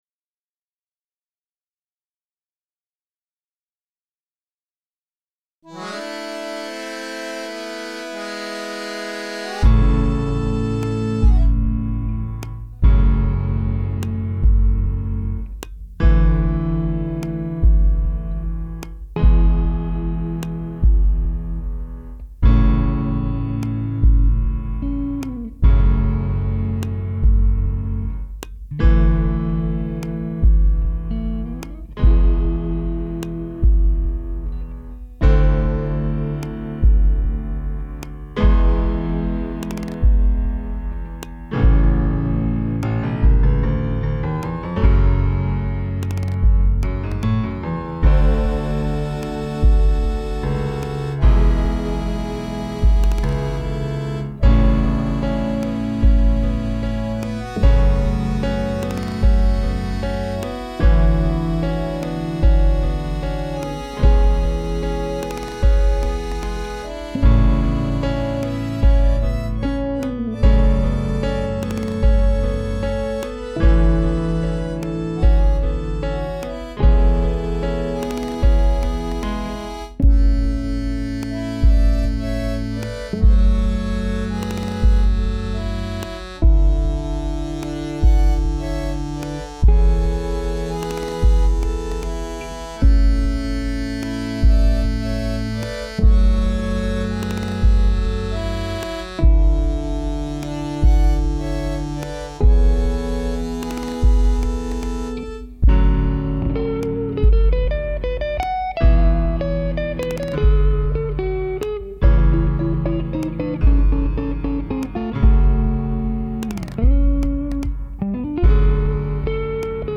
Rendered entirely in Sonar 7 it features 2 real guitar tracks along with a handful of sampled instruments.